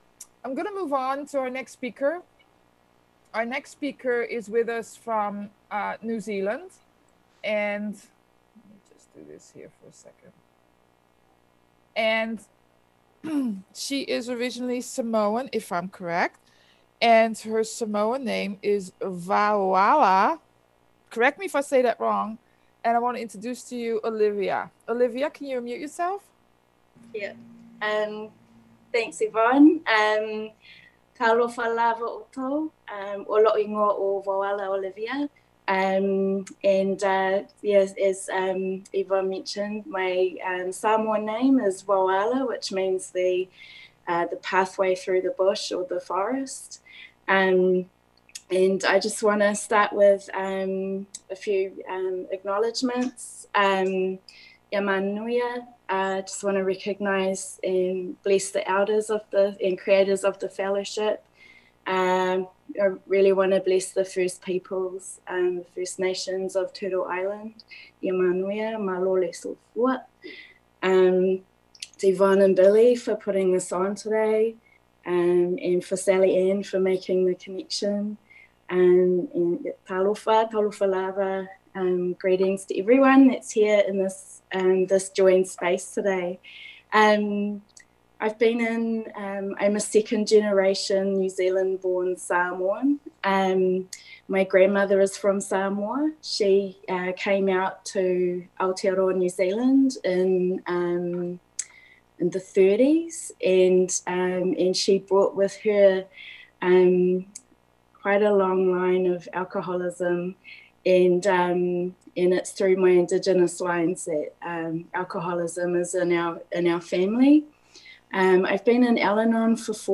American Indian Conference - AWB Roundup Oct 17-18